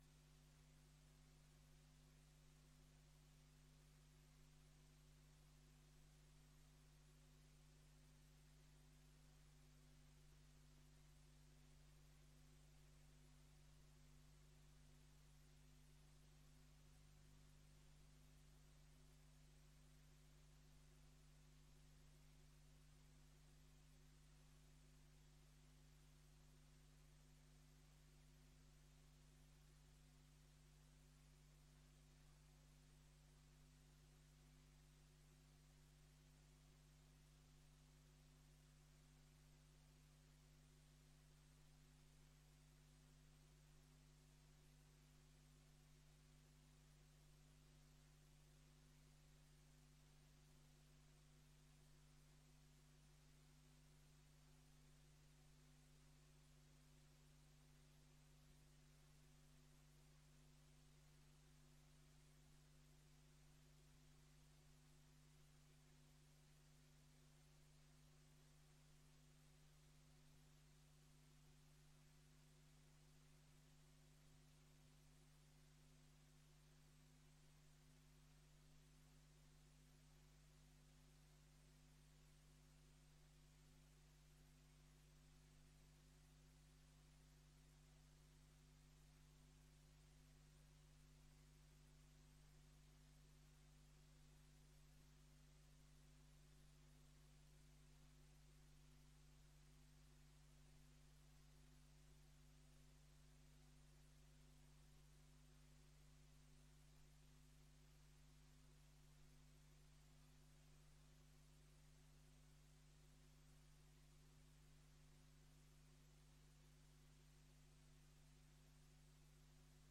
Het college en de leden van het college krijgen de gelegenheid kort het woord te voeren om te kunnen voldoen aan hun actieve informatieplicht.